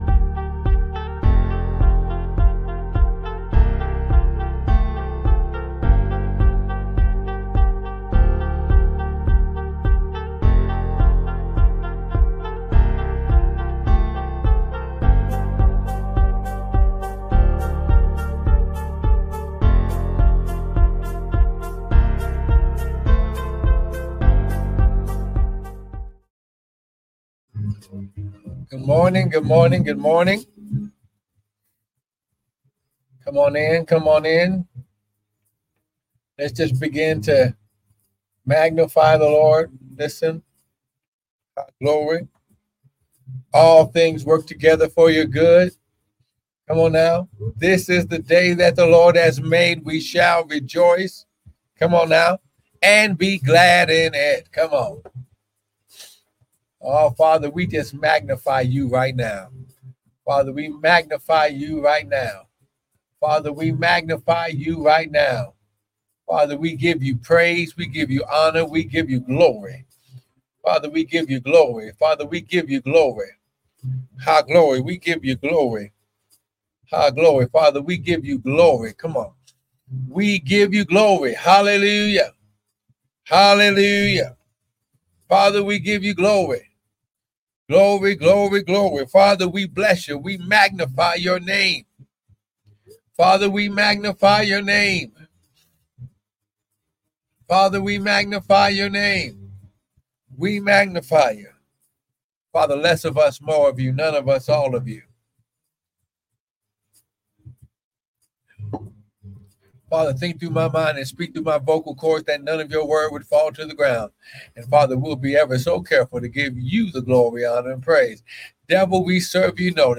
No signup or install needed. live-recording 9/23/2024 7:05:15 AM. live-recording 9/22/2024 7:43:27 AM.